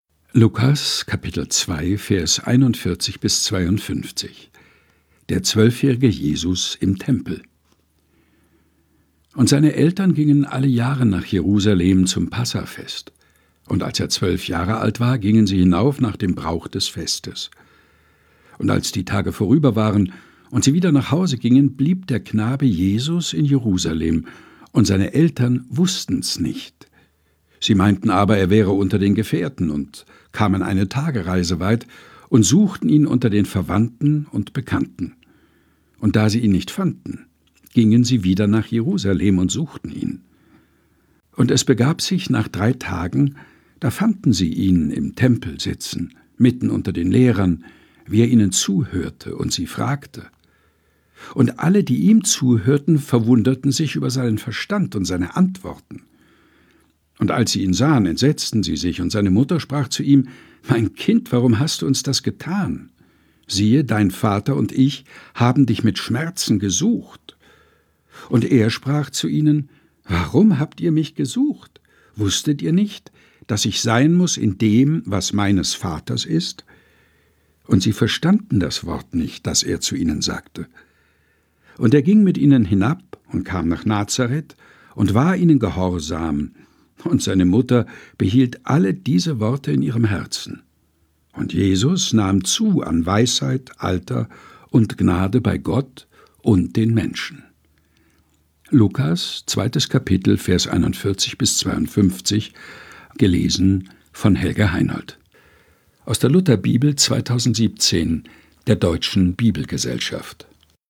improvisierten Studio ein.